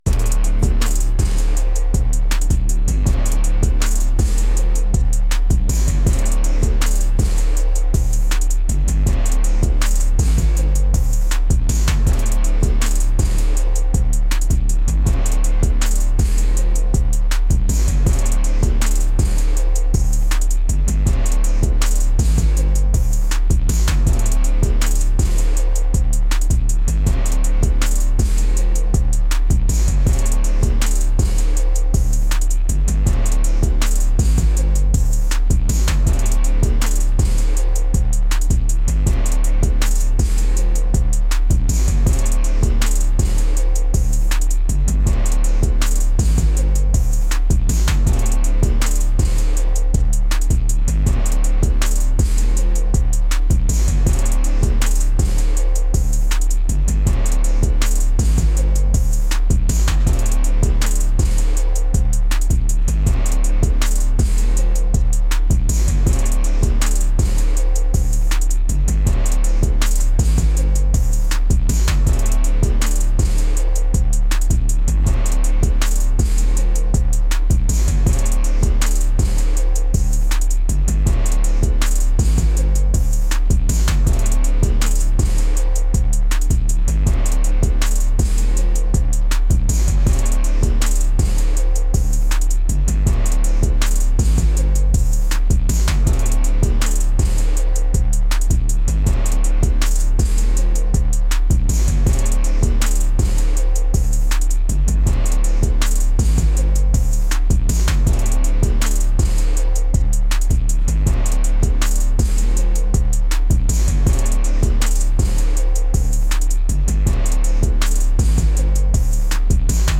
aggressive